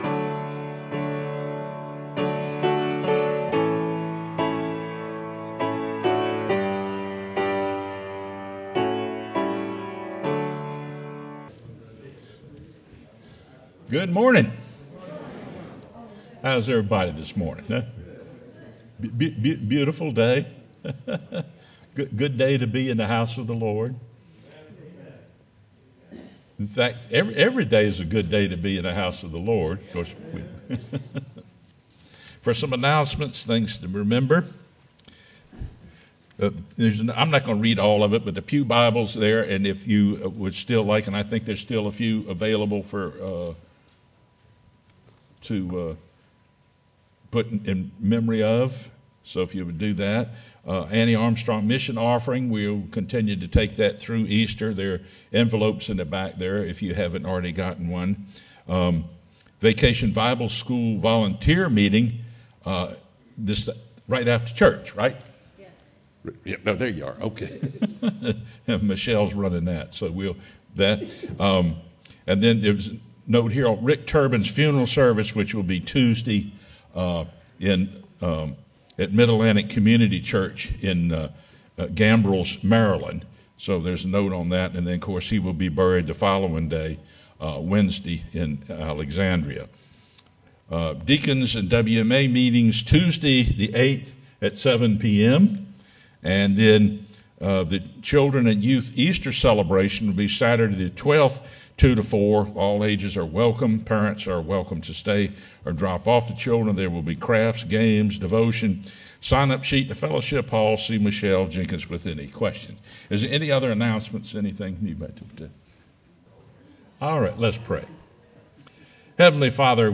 sermonApr06-CD.mp3